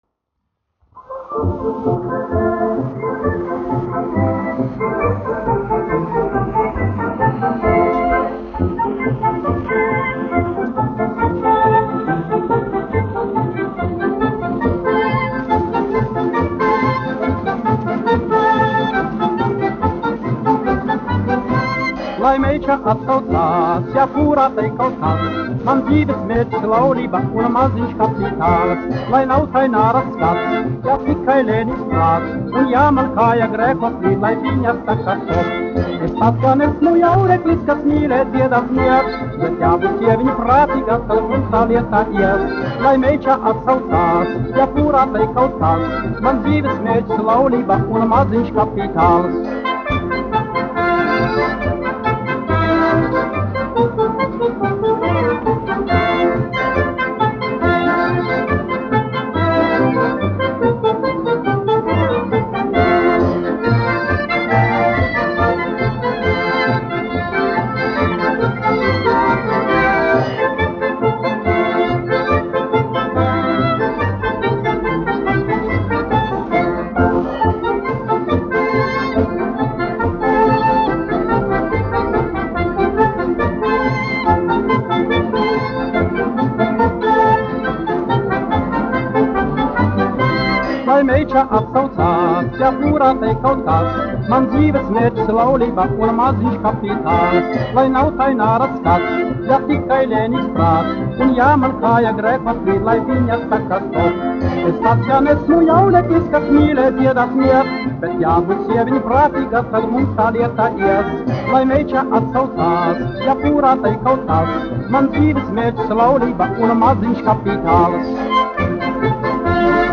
1 skpl. : analogs, 78 apgr/min, mono ; 25 cm
Fokstroti
Populārā mūzika